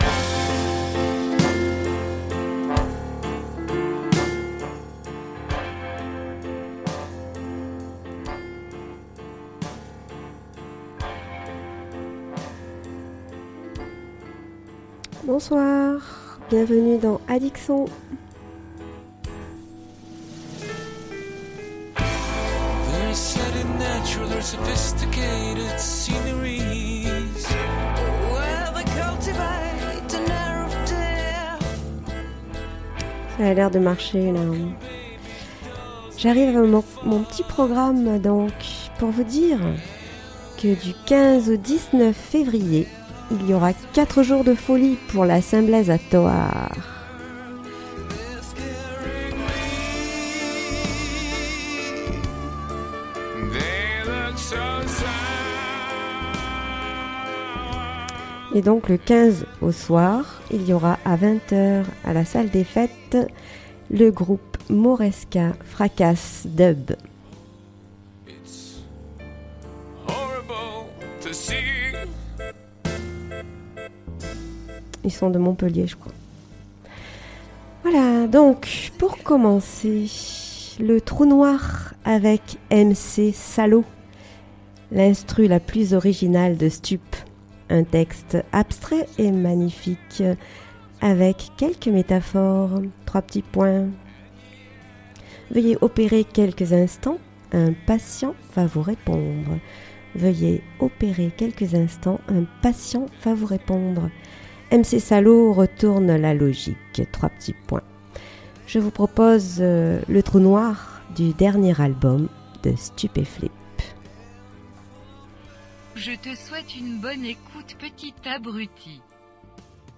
chanson française mais aussi, du blues, du rock, du reggae, du rap, jazz, etc.